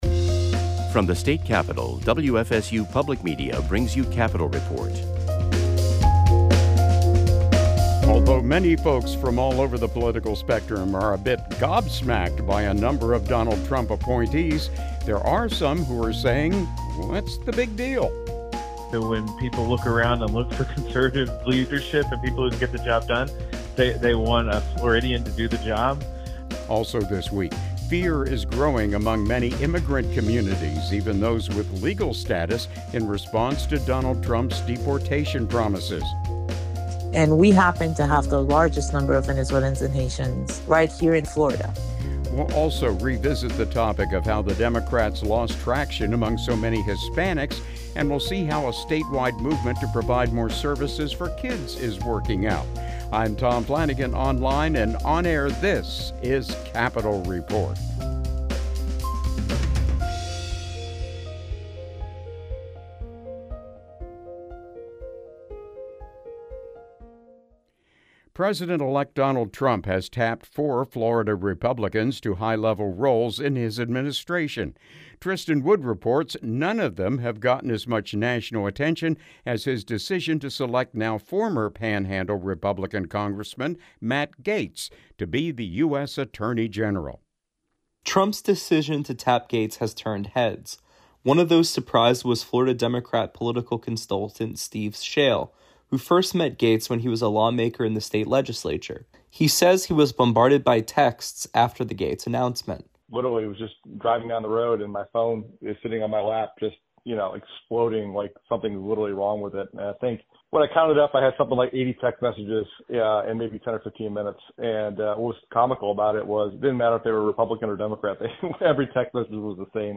WFSU Public Media reporters, as well as reporters from public radio stations across the state, bring you timely news and information from around Florida. Whether it's legislative maneuvers between sessions, the economy, environmental issues, tourism, business, or the arts, Capital Report provides information on issues that affect the lives of everyday Floridians.